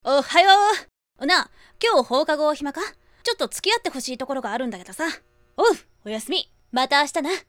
少年も可能ですが、こちらは中性的なイメージとなります。 中高音は透き通った感じ、低音はさっぱりとしっとりを混ぜた感じの声です。 シリアス演技も、ギャグコメディ演技も大好きです。